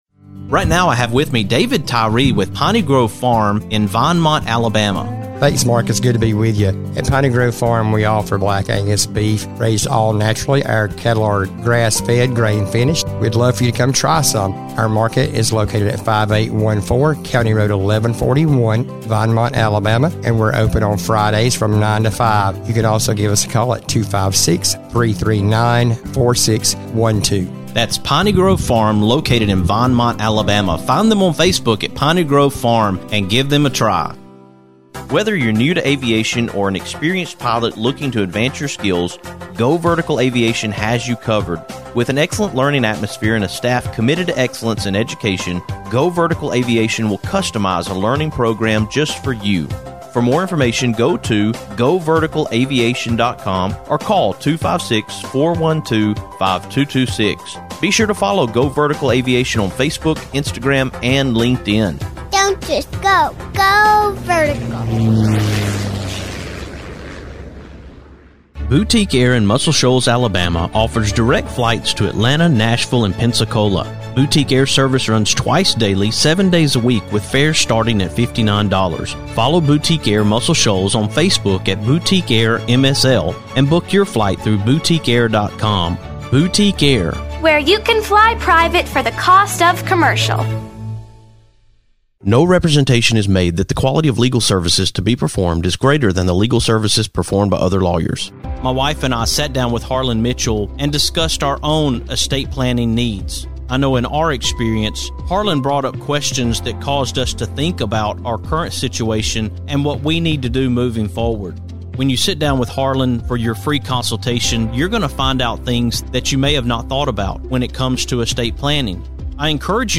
I really enjoyed this conversation